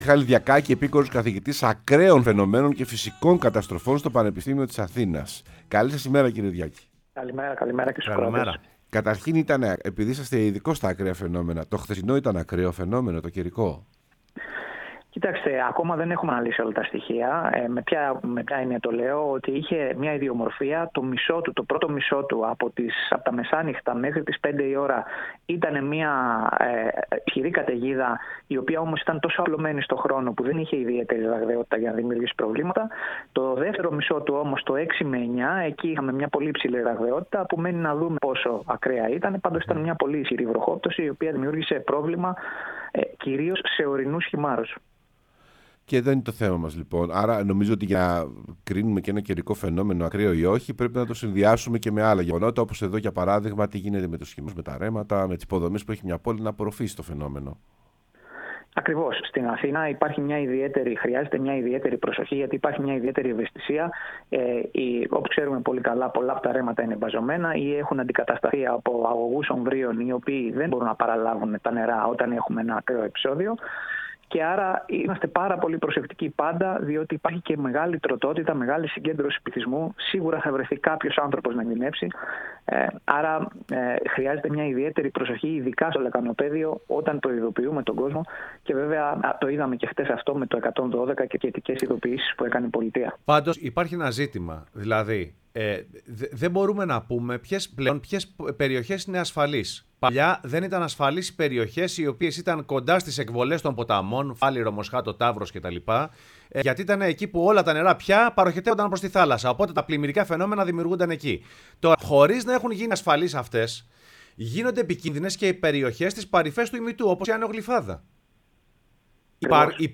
μίλησε στην εκπομπή «Σεμνά και Ταπεινά»